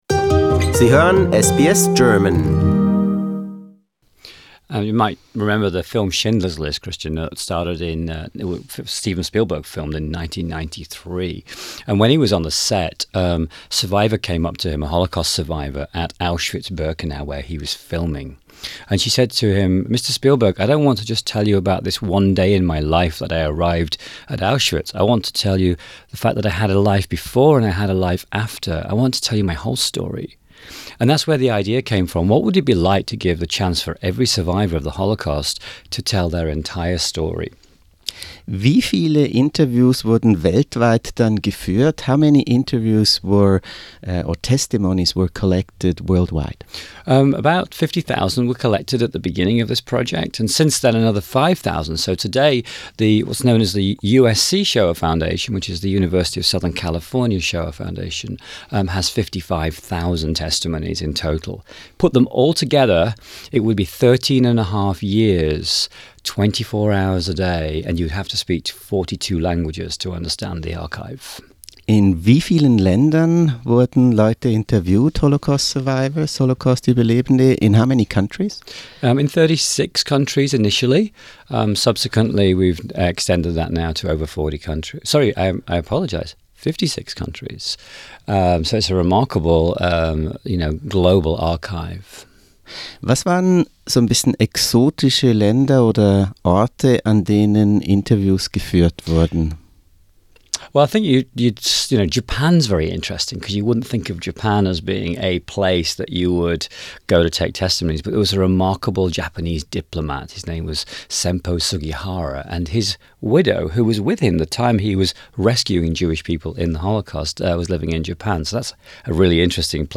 Shoah Foundation in Los Angeles: An interview